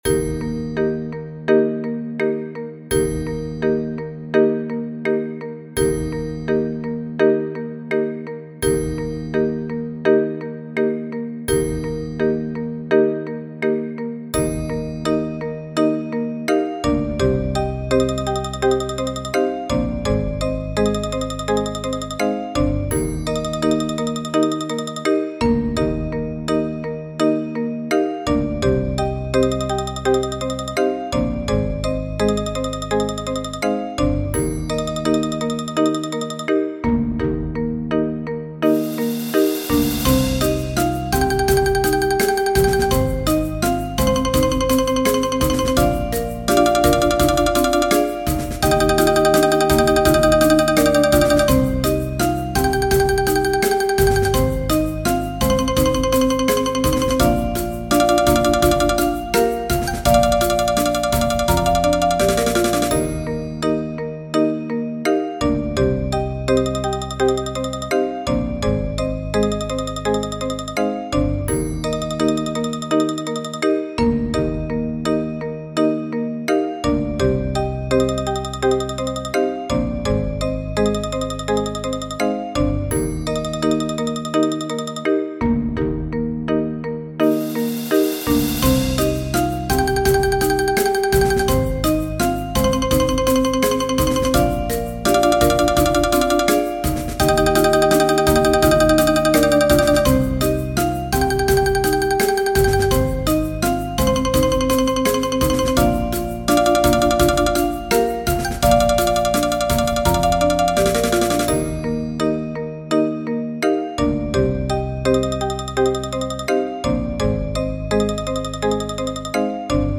Mallet-Steelband